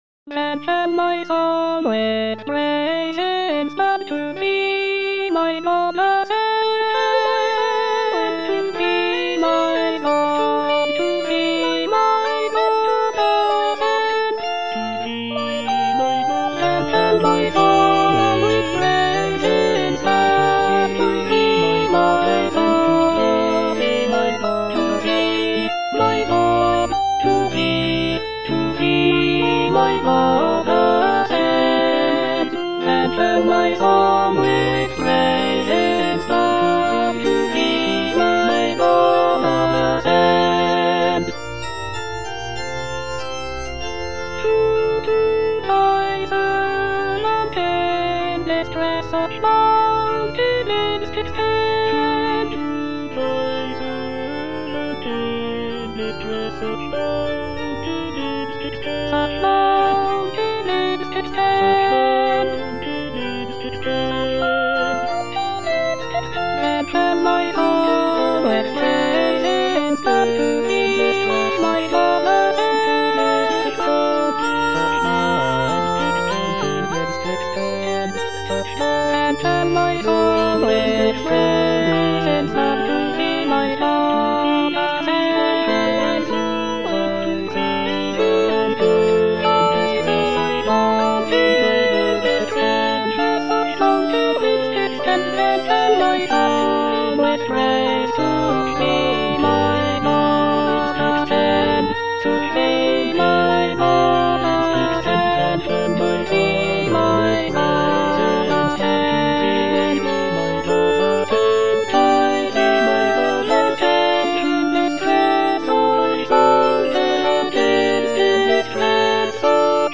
Choralplayer playing In the Lord put I my trust - Chandos anthem no. 2 (SATB version) HWV247 by G.F. Händel (arr. D. Cranmer) based on the edition Novello Publishing Ltd. NOV060137
G.F. HÄNDEL - IN THE LORD PUT I MY TRUST HWV247 (SATB VERSION) Then shall my song, with praise inspir'd - Alto (Emphasised voice and other voices) Ads stop: auto-stop
It features a four-part chorus (soprano, alto, tenor, and bass) and is set to a biblical text from Psalm 11, expressing trust and reliance on the Lord.